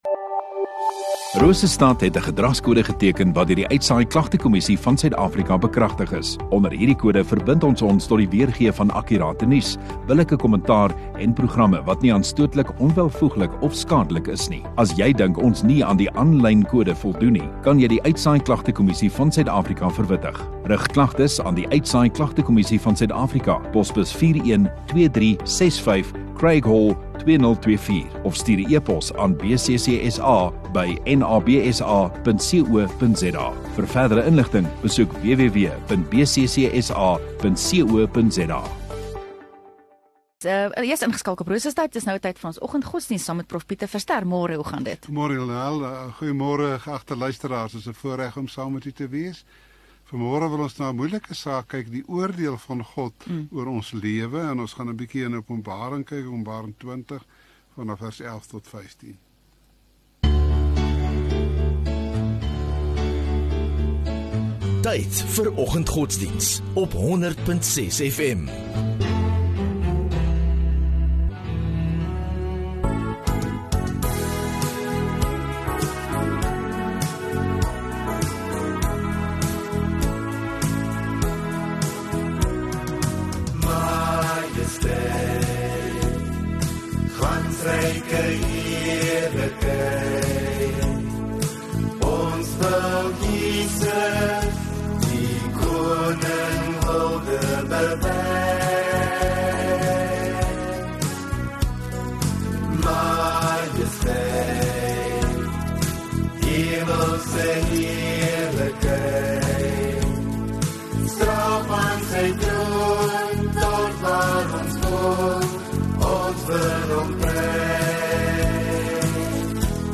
21 Jun Vrydagoggend Oggenddiens